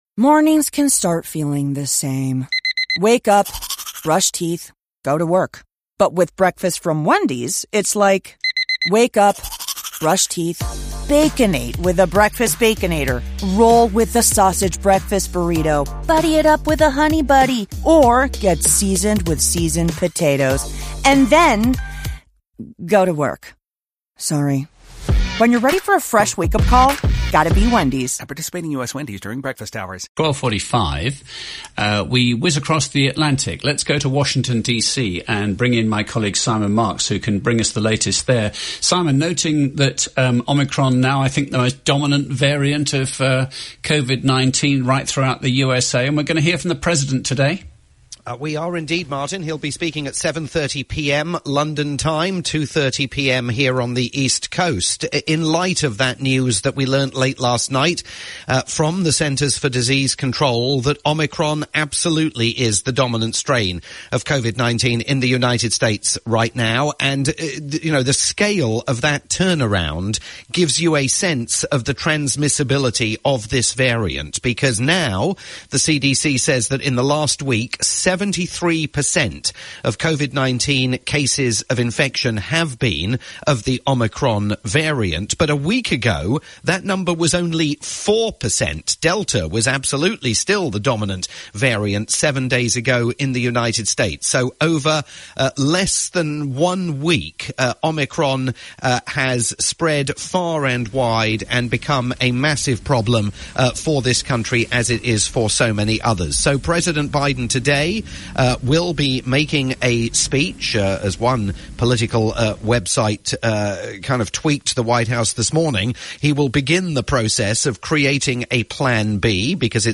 live report